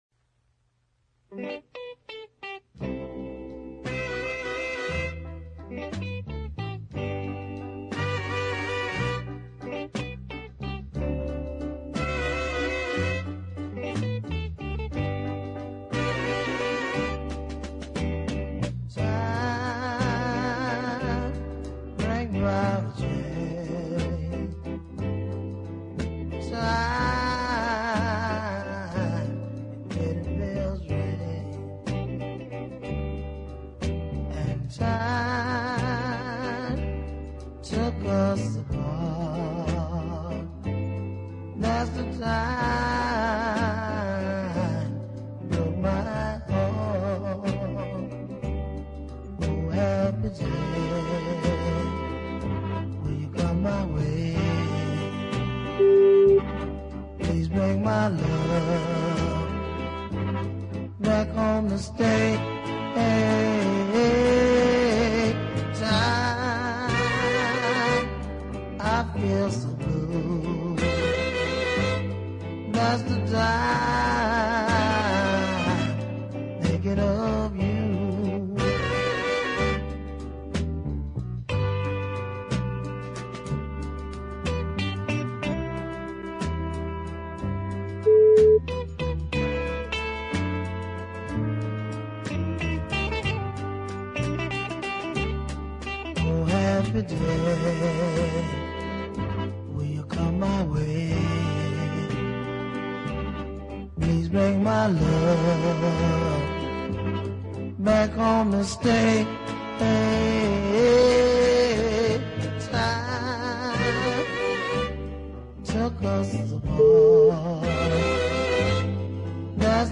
deep ballad
downbeat vocal, hoarse and so effective